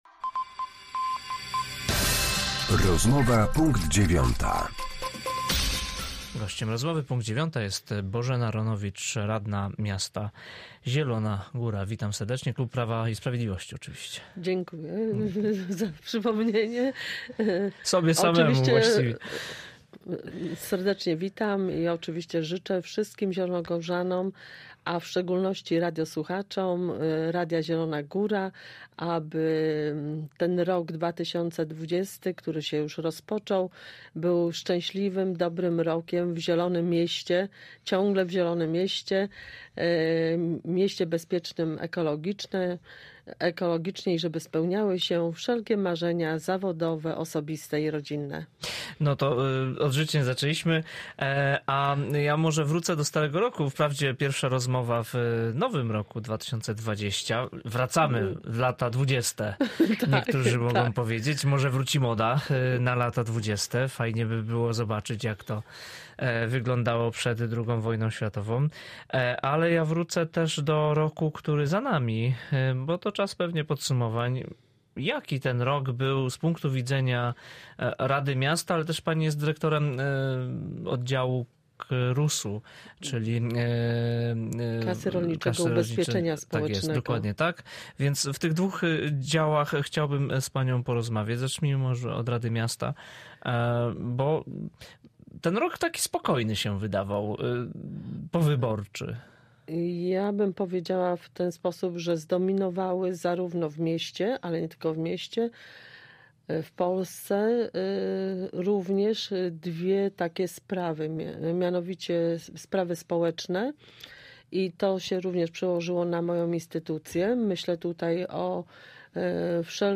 Z radną klubu Prawo i Sprawiedliwość rozmawiał